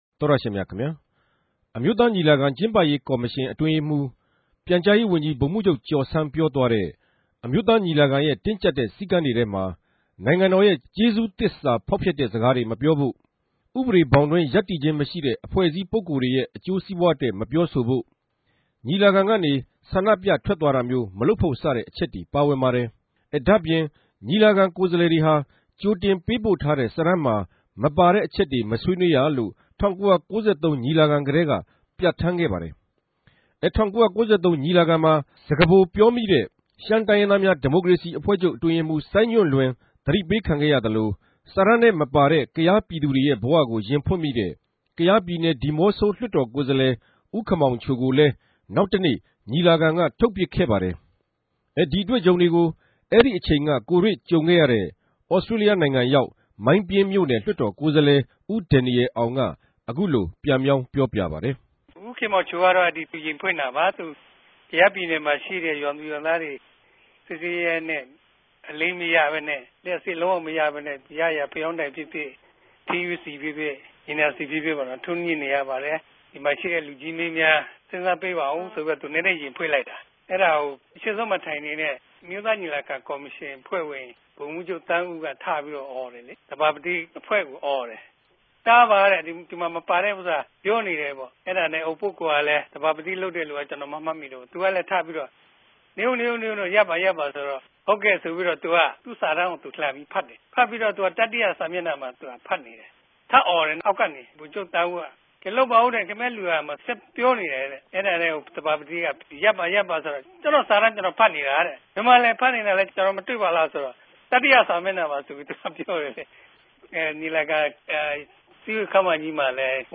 ထိုင်းိံိုင်ငံ ဗန်ကောက်္ဘမိြႚ RFA႟ုံးခြဲကနေ